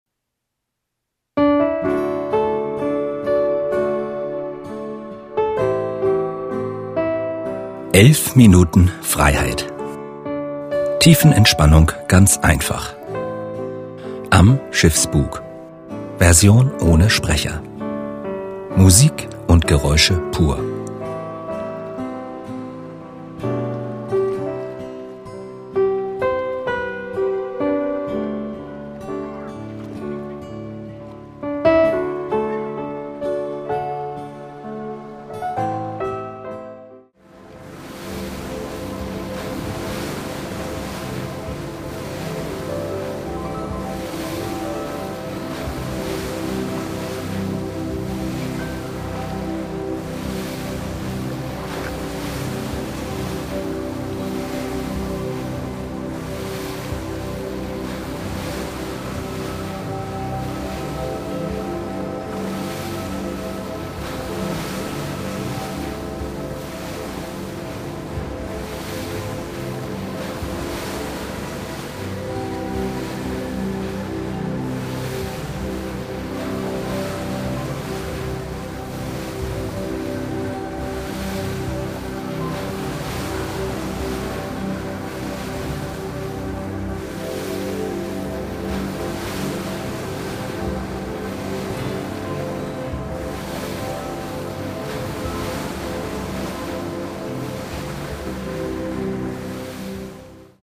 Entspannungsmusik und Klänger pur   11:00 min